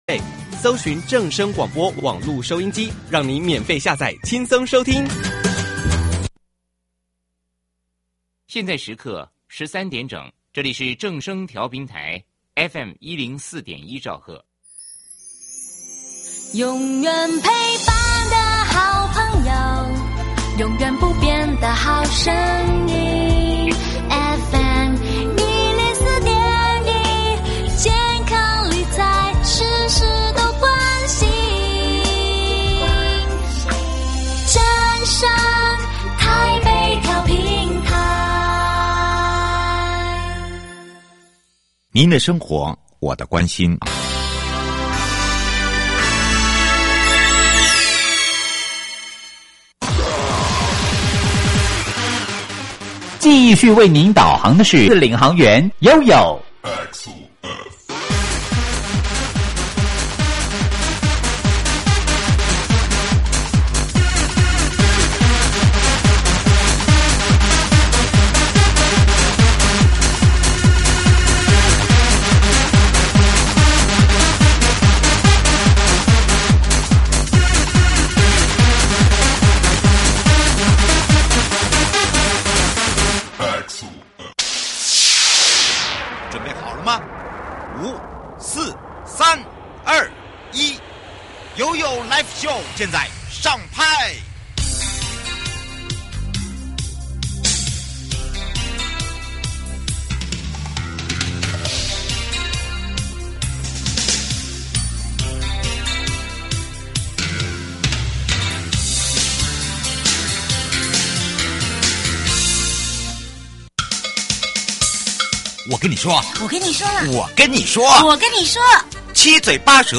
受訪者： 營建你我他 快樂平安行~七嘴八舌講清楚~樂活街道自在同行! 【主題】#《行人交通安全設施條例》上路